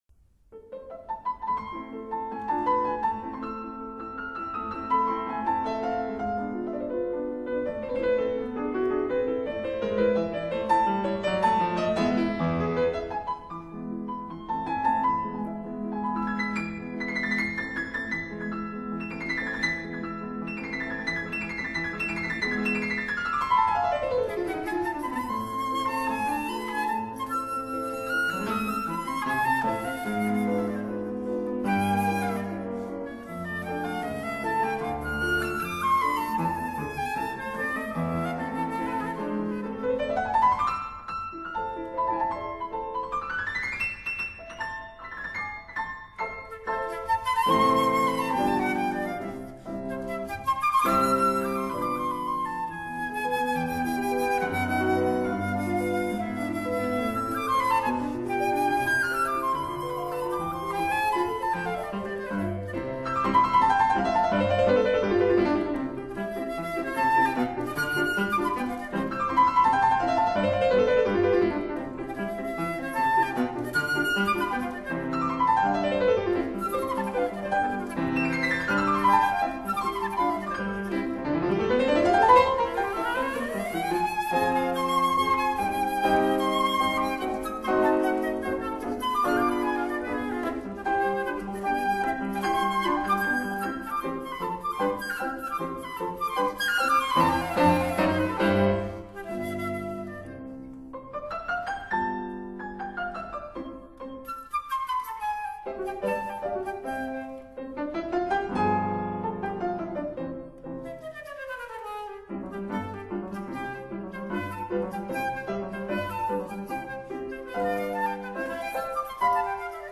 for flute and piano
Rondo_ Allegro poco agitato